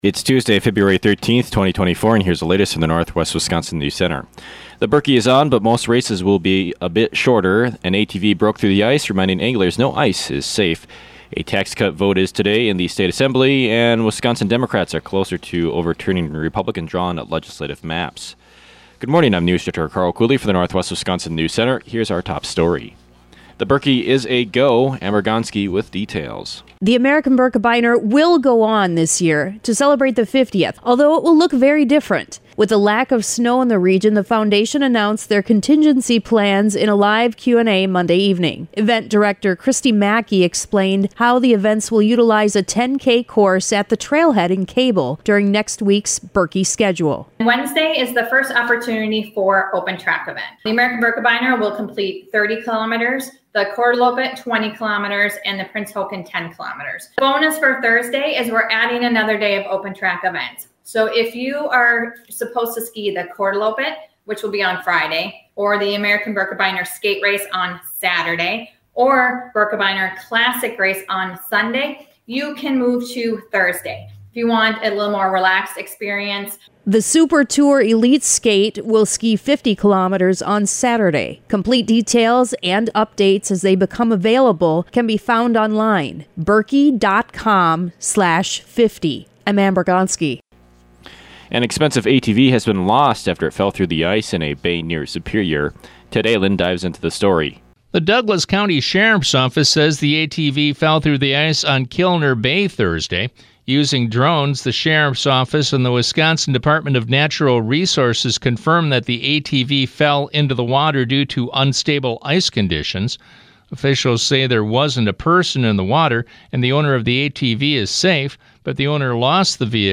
AM NEWSCAST – Tuesday, Feb. 13, 2024 | Northwest Builders, Inc.